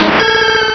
Cri de Méganium dans Pokémon Diamant et Perle.